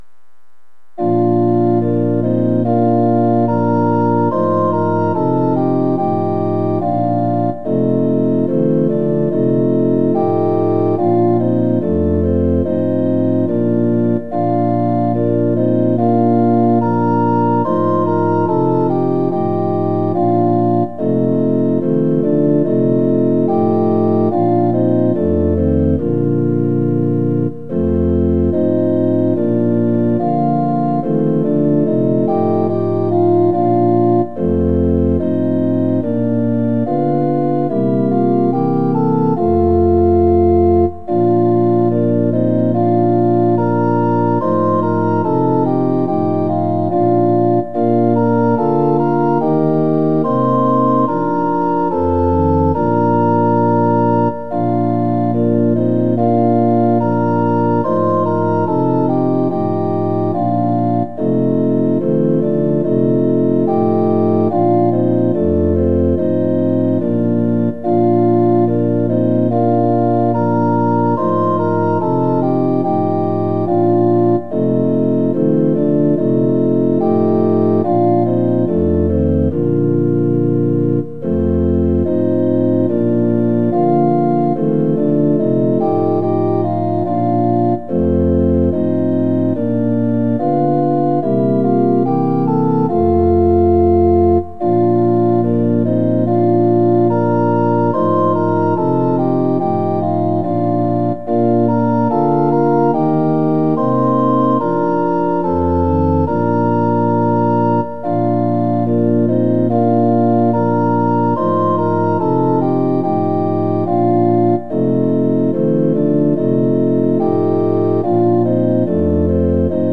◆４分の４拍子　：　１拍目から始まります。